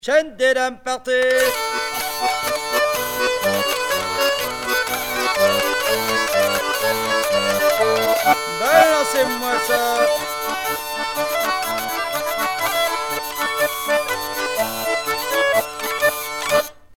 danse : quadrille : chaîne des dames
Pièce musicale éditée